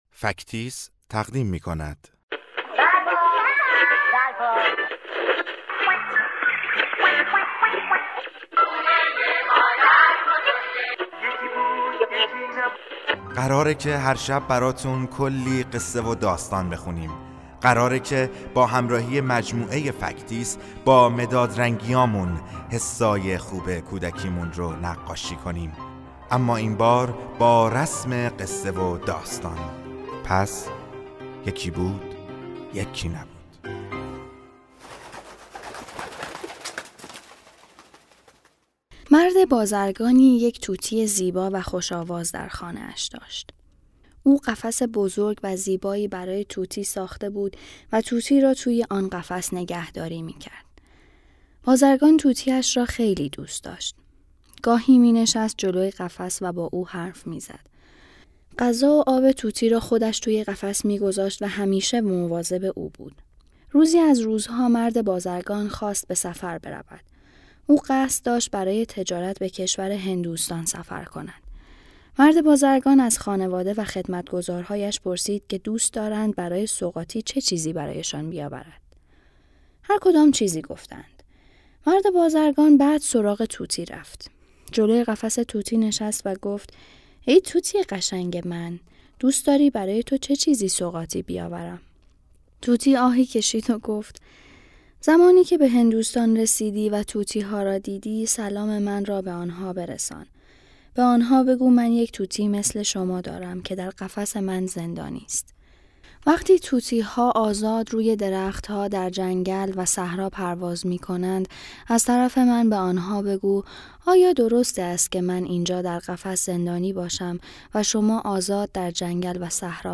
قصه کودکانه صوتی طوطی و بازرگان